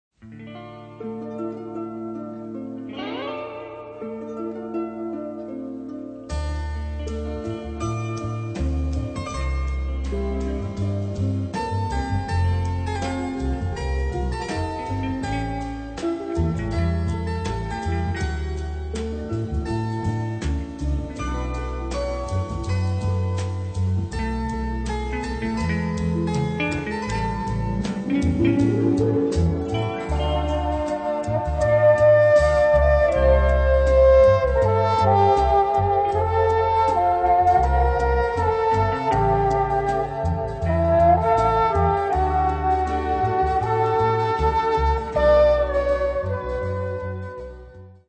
Zwei Soundtracks zu deutschen Aufklärungsfilmen (1968/69)